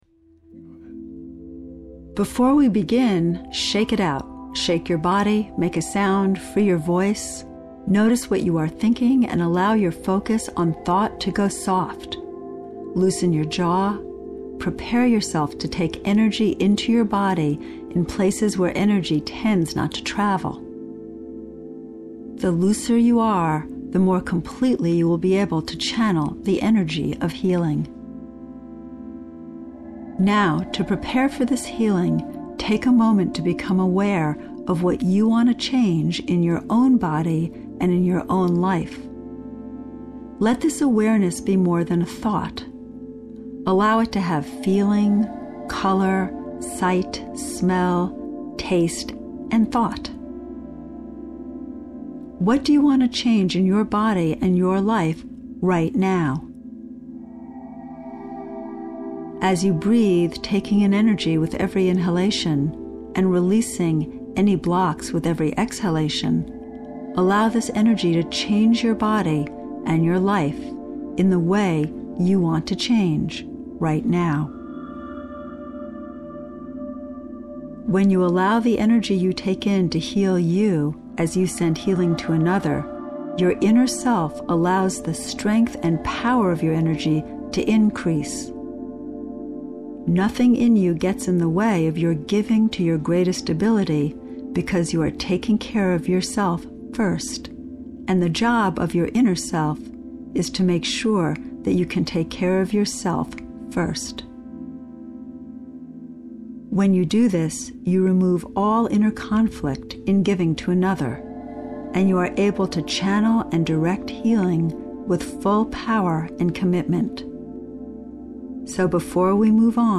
EXPERIENCE Meditation Audio The following audio recordings are engineered to create states of relaxation, intuition and healing, and to enable you to better direct your energy to positive change.